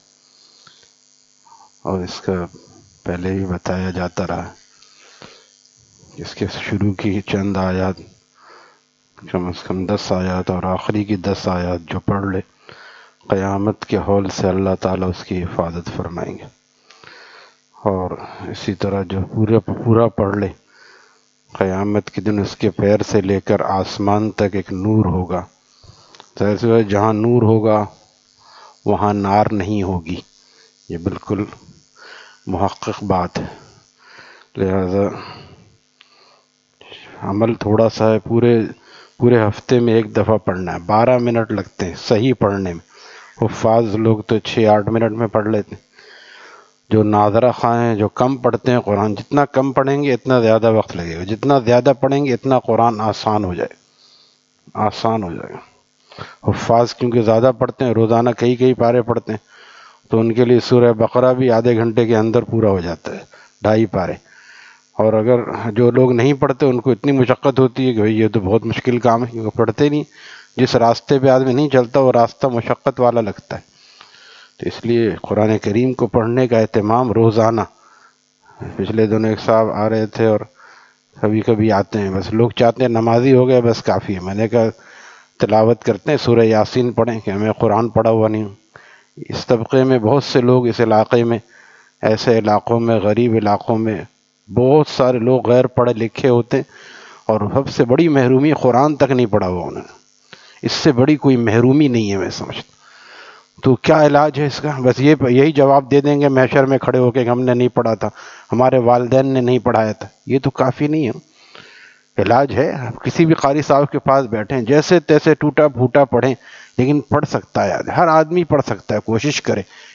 Taleem After Fajar at Jamia Masjid Gulzar e Muhammadi, Khanqah Gulzar e Akhter, Sec 4D, Surjani Town